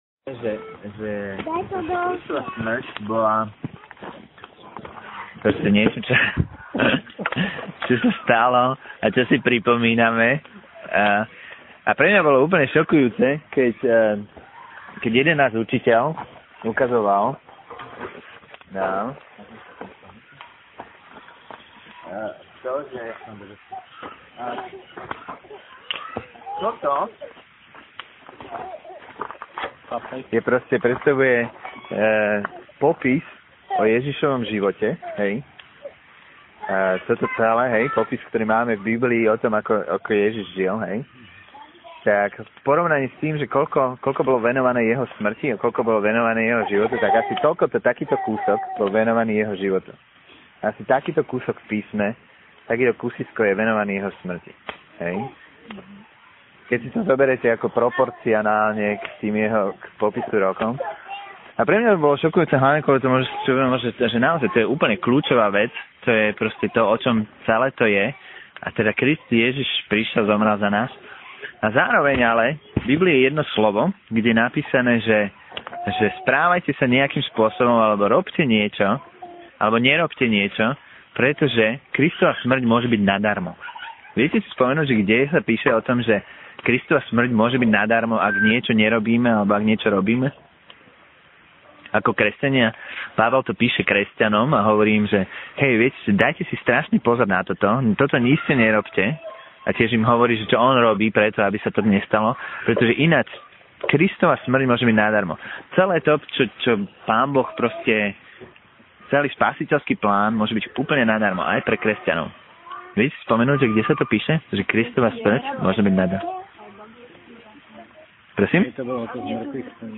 Nahrávka kázne Kresťanského centra Nový začiatok z 6. apríla 2007
O tom, čo pre nás znamená Kristova obeť. (Nahrávané mobilom v prírode)